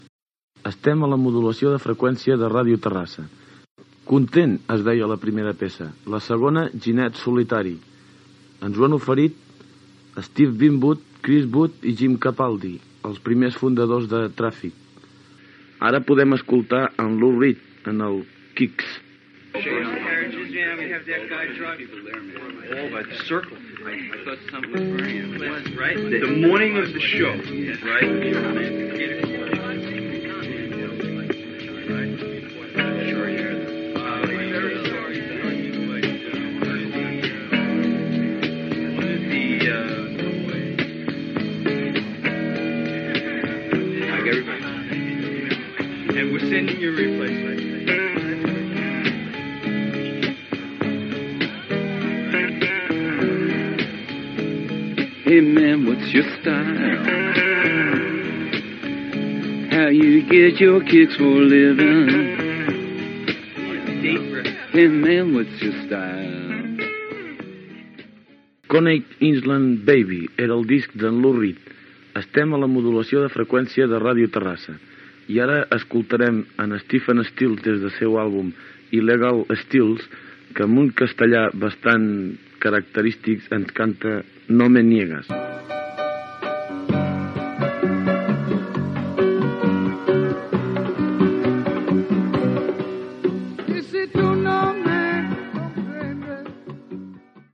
Identificacions de l'emissora i presentació de temes musicals
Musical